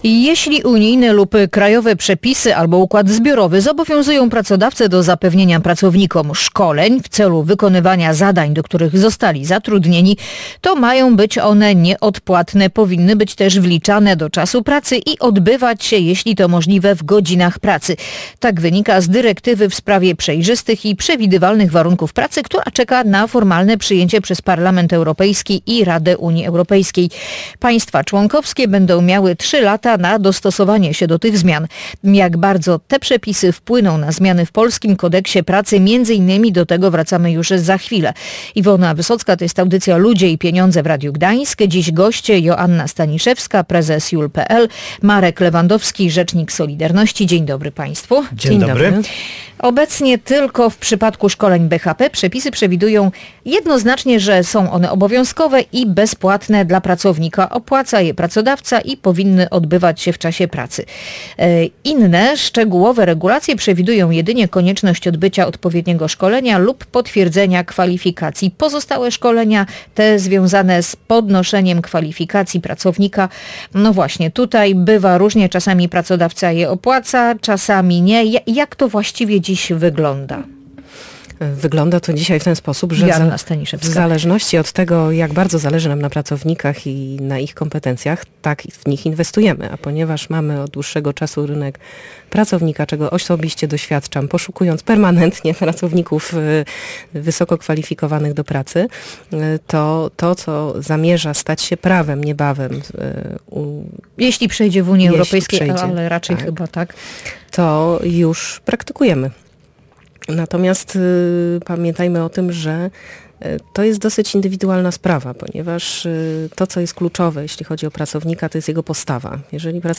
W audycji Ludzie i Pieniądze dyskutowali: